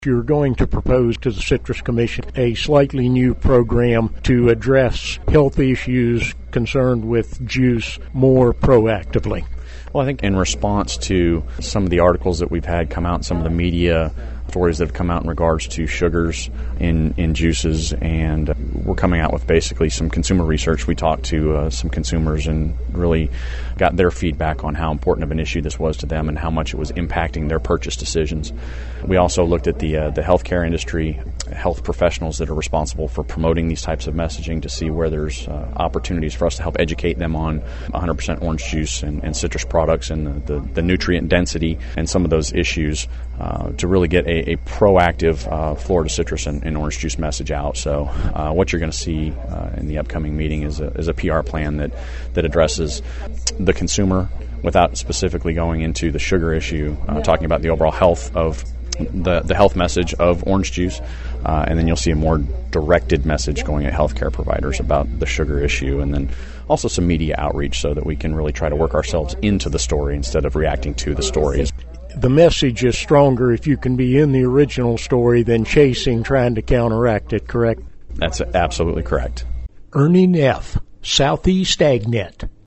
Media reports indicating that fruit juices contain as much or even more sugar than soft drinks could put a dent in Florida citrus juice sales. Florida Department of Citrus Executive Director Doug Ackerman in this report discusses a proactive plan the department will present to its governing board – the Florida Citrus Commission – Wednesday.